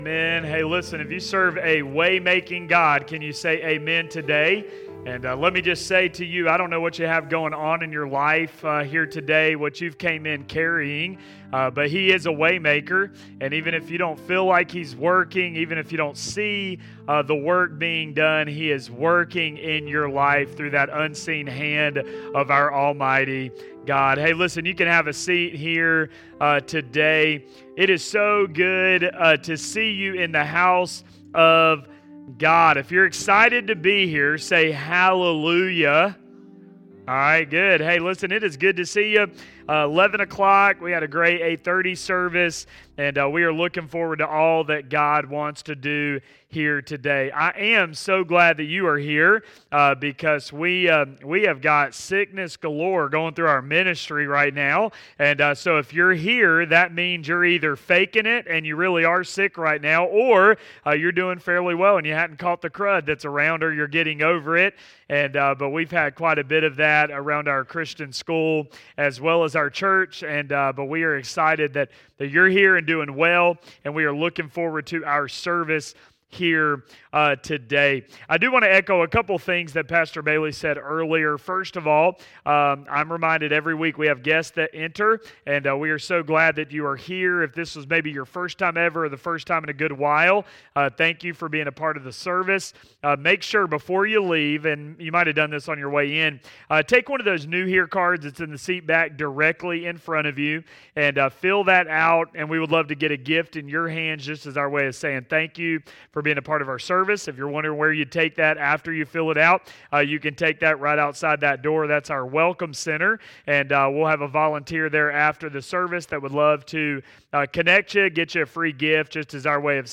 In this sermon, he looks at the importance of serving and using your spiritual gifts.